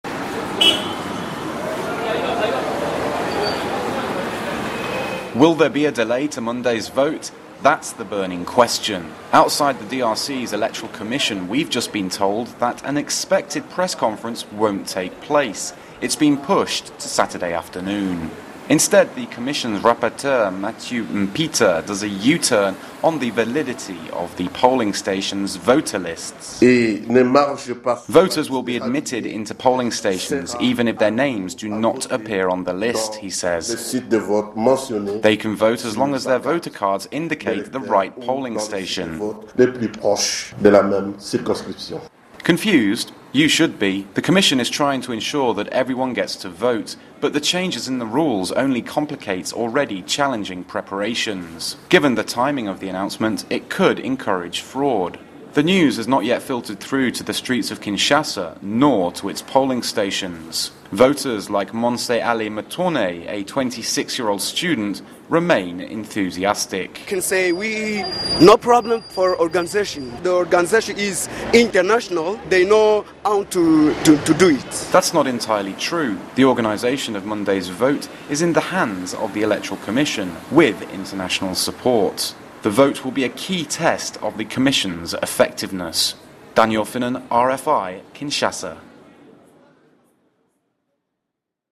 Report: DRC elections – Possible delay
report_drc_elections_-_possible_delay.mp3